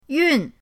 yun4.mp3